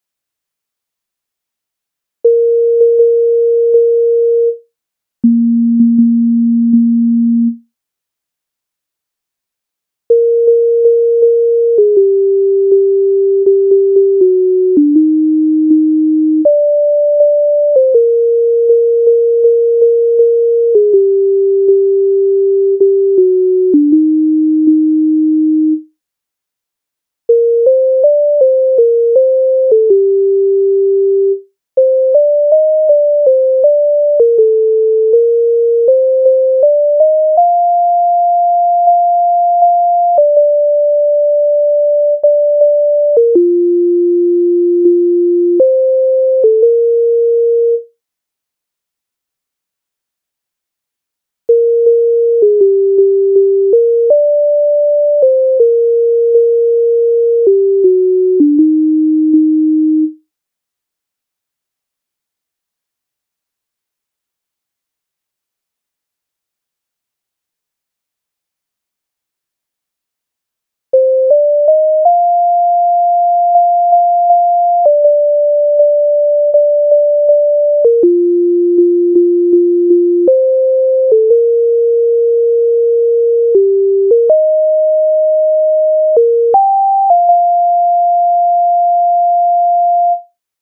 Арія з опери